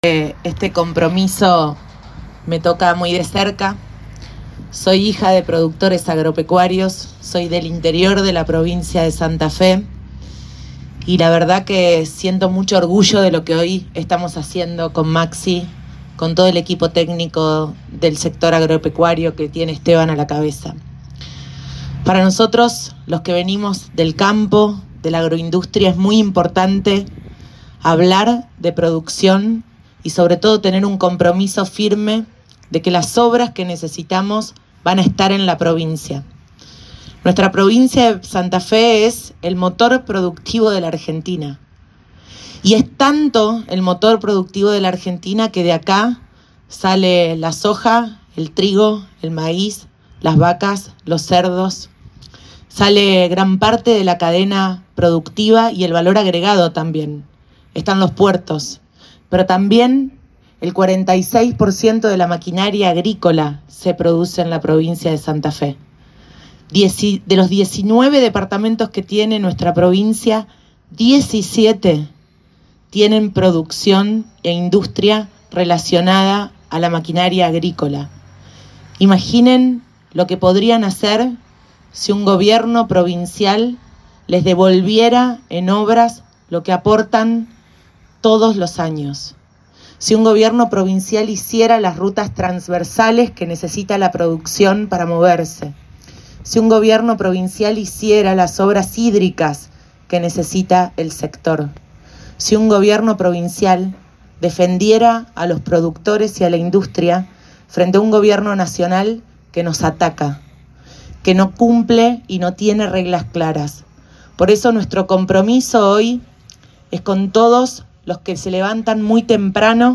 Luego dirigio la palabra la candidate a Vicegobernadora Gisela Scaglia quien resalto de la importancia que significa Santa Fe para el país, de la urgencia de poner a andar a esta provincia tan mal manejada hasta el momento.